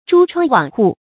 珠窗網戶 注音： ㄓㄨ ㄔㄨㄤ ㄨㄤˇ ㄏㄨˋ 讀音讀法： 意思解釋： 飾珠、鏤花的門窗。